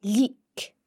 The slender L can also be heard in leig (let):